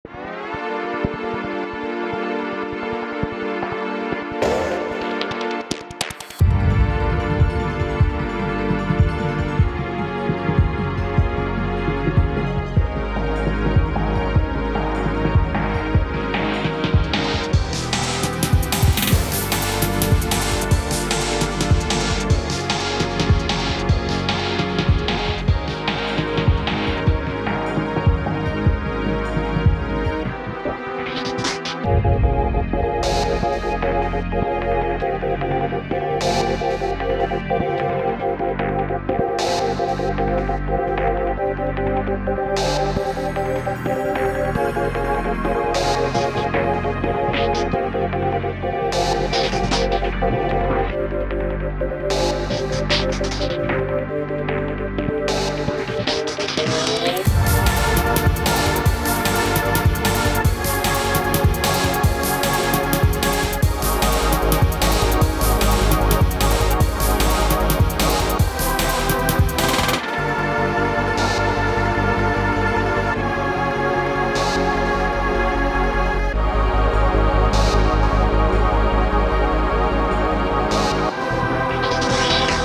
dnb drum bass amen break
instrumental